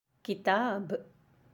How to say book in Arabic